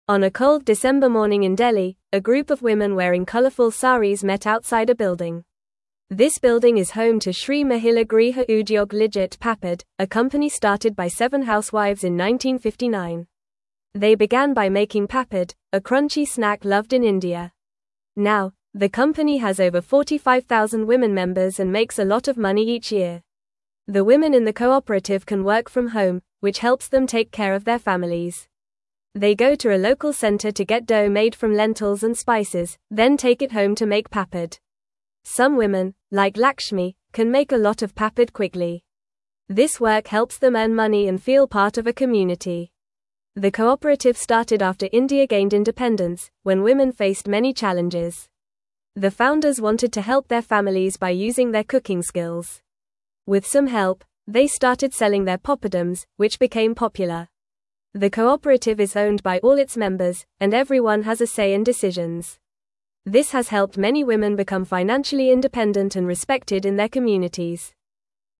Fast
English-Newsroom-Lower-Intermediate-FAST-Reading-Women-in-India-Make-Tasty-Papad-Together.mp3